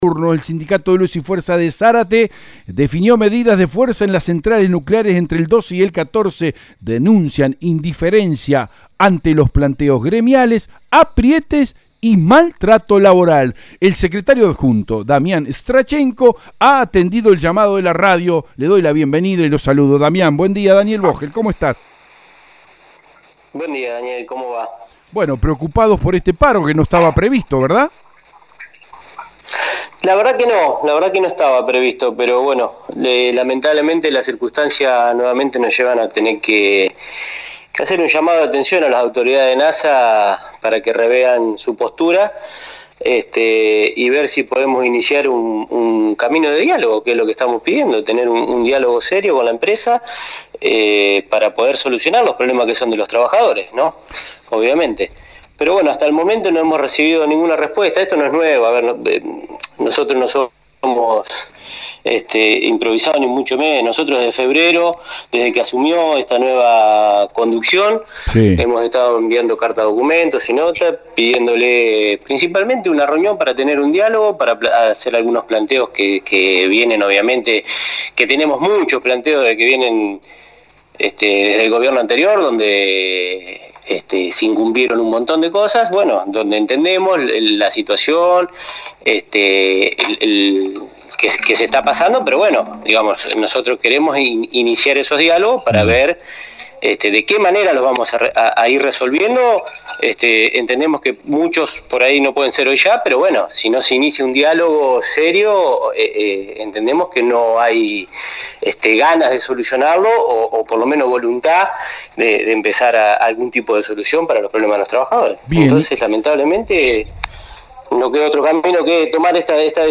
en el programa de la mañana de radio EL DEBATE